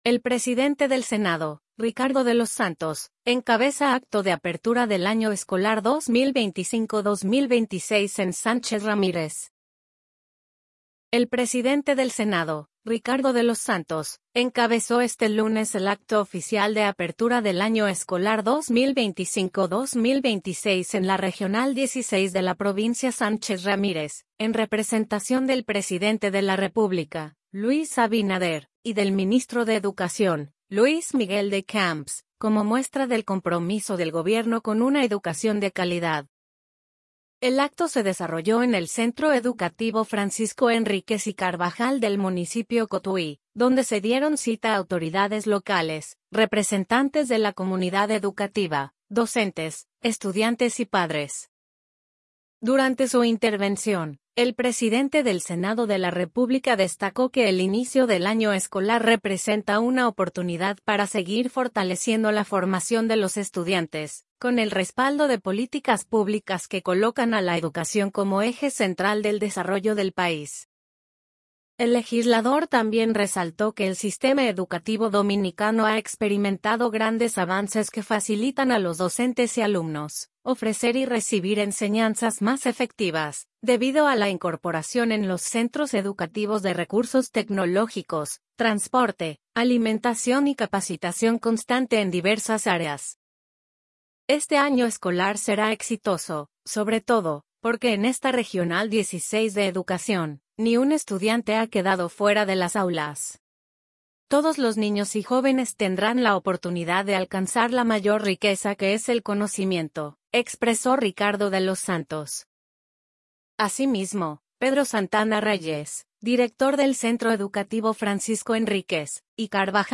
El presidente del Senado, Ricardo de los Santos, encabeza acto de apertura del año escolar 2025-2026 en Sánchez Ramírez
El acto se desarrolló en el Centro Educativo Francisco Henríquez y Carvajal del municipio Cotuí, donde se dieron cita autoridades locales, representantes de la comunidad educativa, docentes, estudiantes y padres.